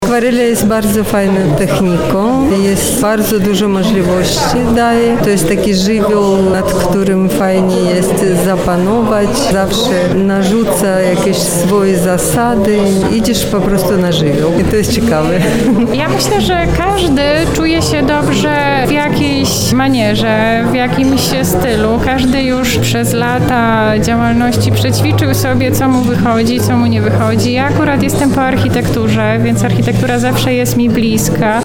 O dziełach mówiły też obecne na otwarciu autorki prac